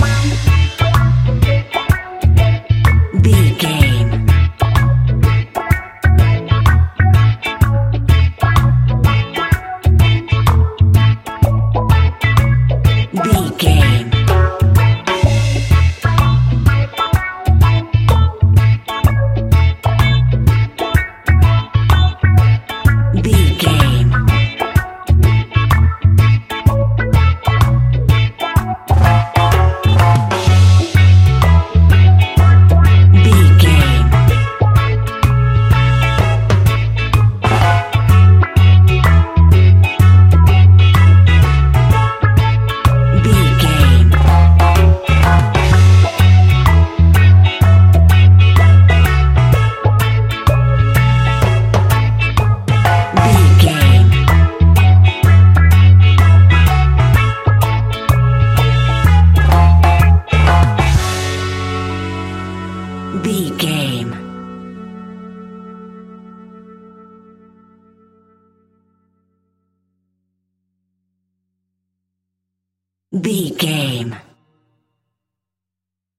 Classic reggae music with that skank bounce reggae feeling.
Uplifting
Aeolian/Minor
F#
instrumentals
laid back
chilled
off beat
drums
skank guitar
hammond organ
percussion
horns